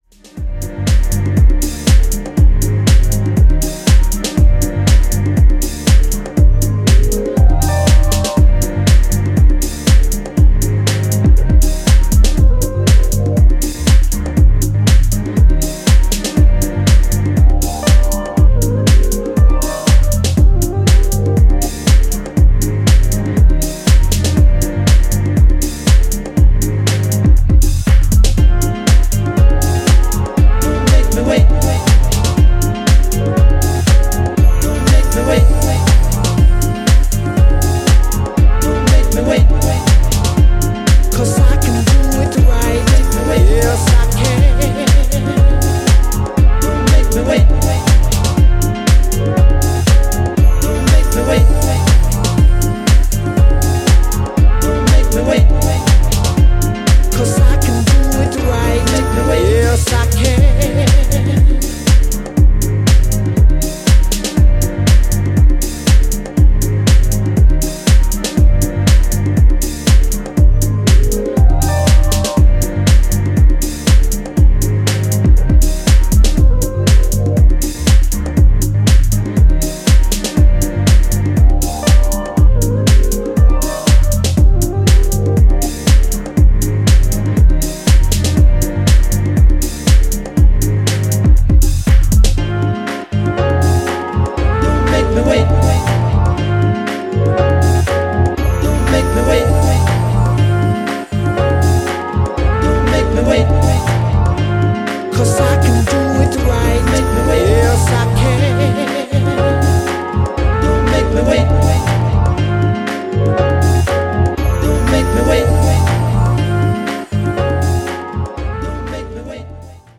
ジャンル(スタイル) DEEP HOUSE / HOUSE / DETROIT